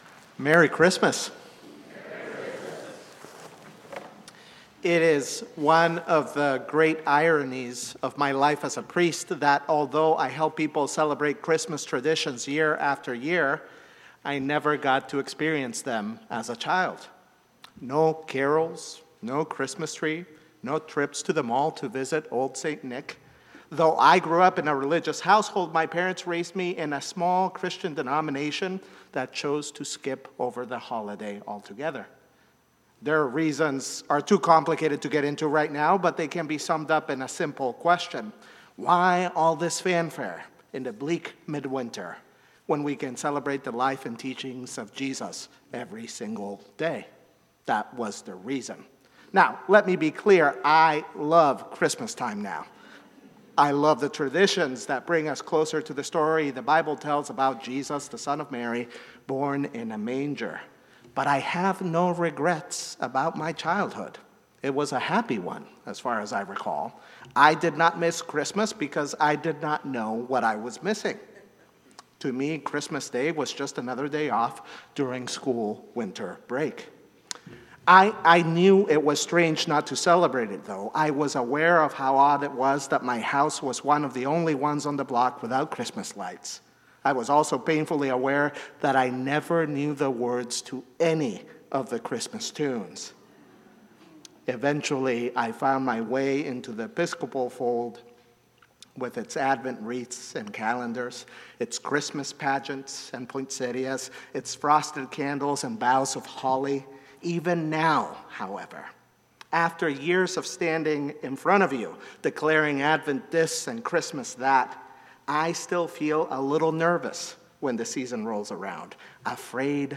St-Pauls-HEII-7p-Homily-24DEC24.mp3